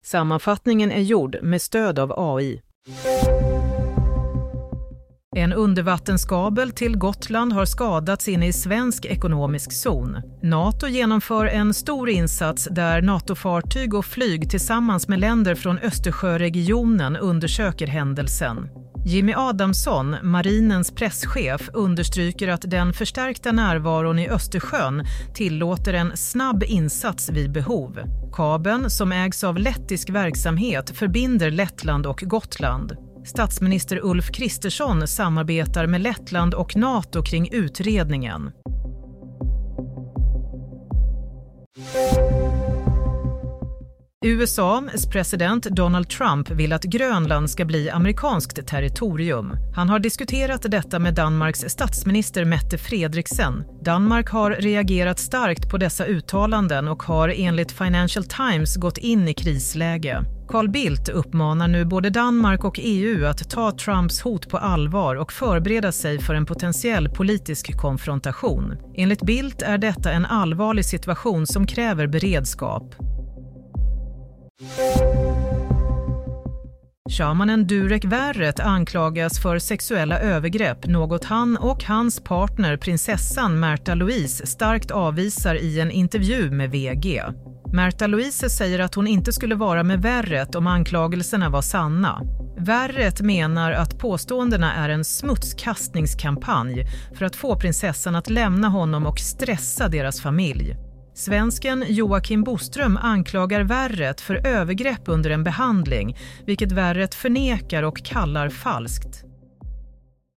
Nyhetssammanfattning – 26 januari 16.00